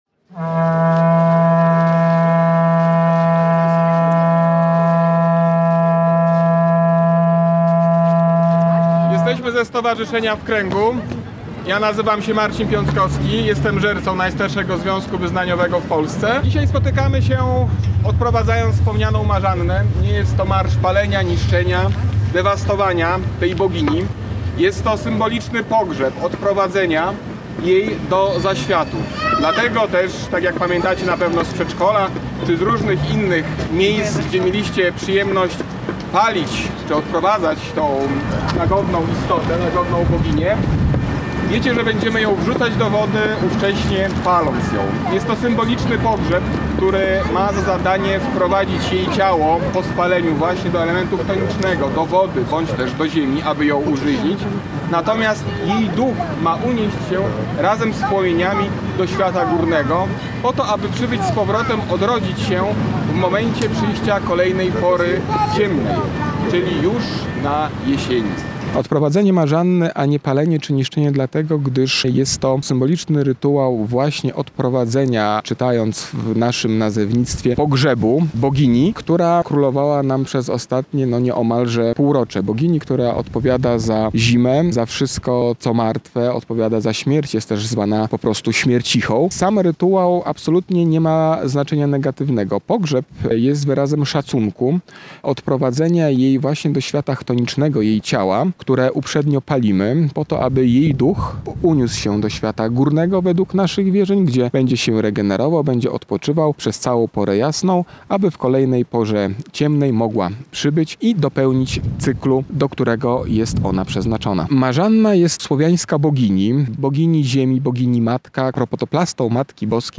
Odprowadzanie Marzanny to dobrze znane wszystkim powitanie wiosny i pożegnanie zimy. W sobotę 22 marca Olsztyn pożegnał swoją Marzannę, odprowadzając ją sprzed ratusza do mostu św. Jana Nepomucena. Ten zwyczaj jest związany z Jarymi Godami, jednym ze świąt słowiańskich.
Topienie-marzanny-Slowianie.mp3